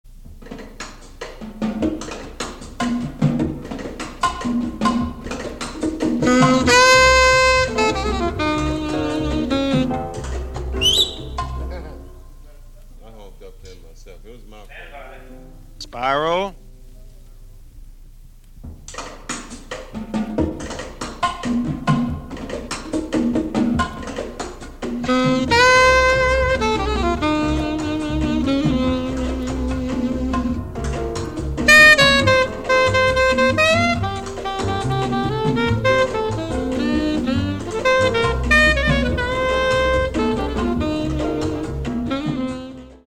bebop-Latin
Its sweet, sunny melody floats over the groove